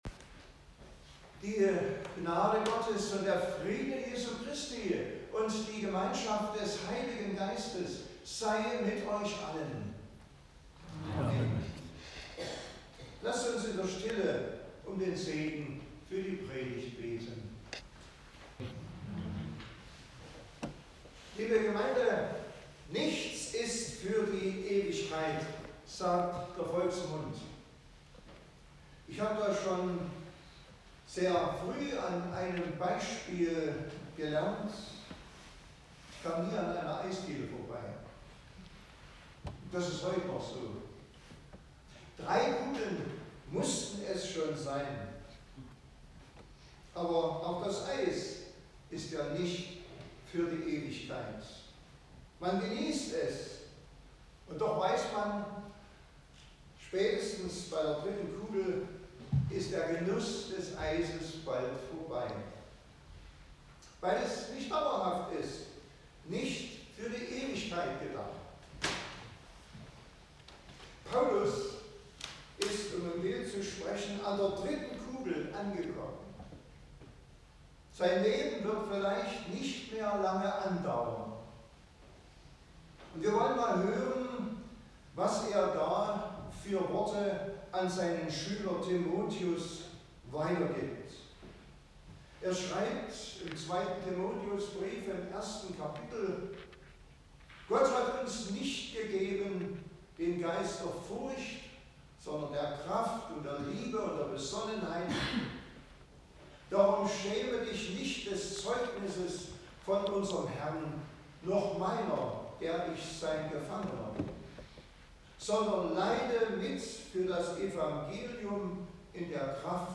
Passage: 2. Timotheus 1; 7-10 Gottesdienstart: Predigtgottesdienst Wildenau « Wovor haben Sie Angst?